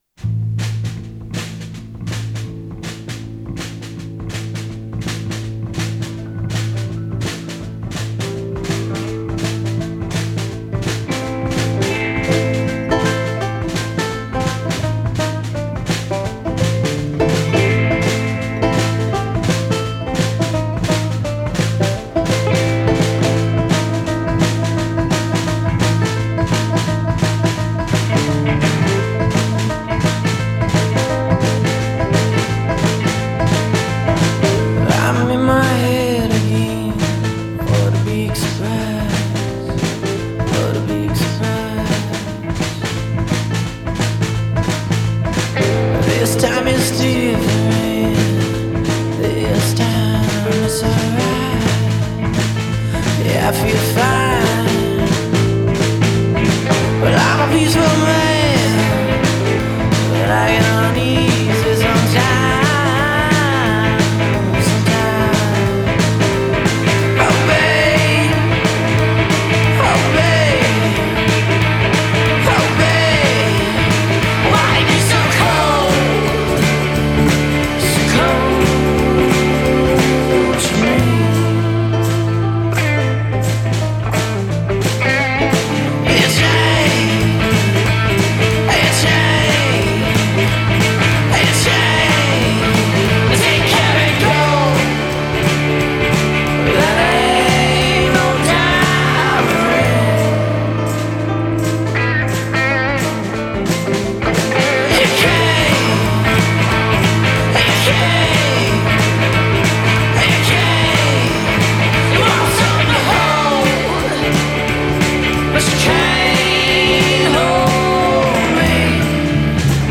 hazy Blues, stomping Folk, and rowdy Rock and Roll